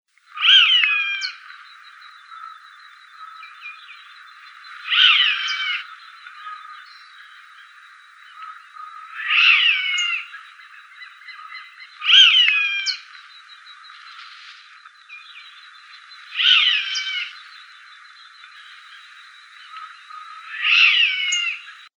Chimango Caracara (Daptrius chimango)
Life Stage: Adult
Location or protected area: Reserva Ecológica Costanera Sur (RECS)
Condition: Wild
Certainty: Recorded vocal
Recs.Chimango.mp3